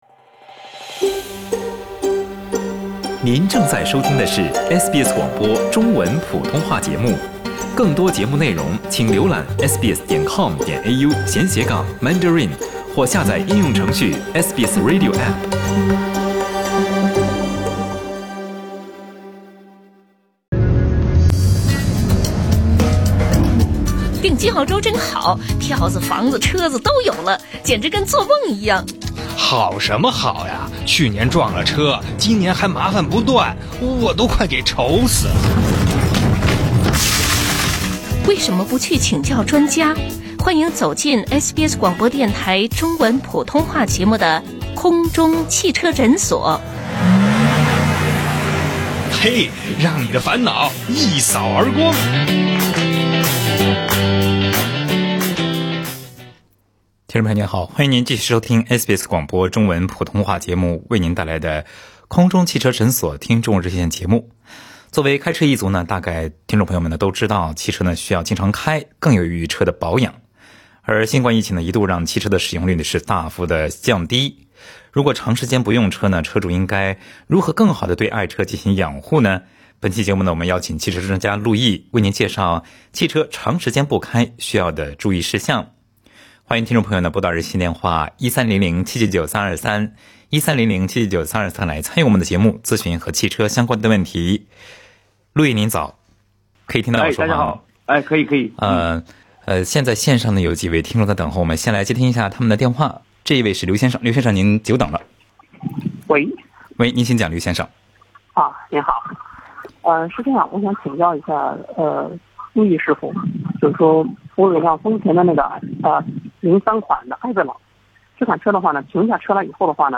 请您点击文首图片收听本期《空中汽车诊所》热线节目。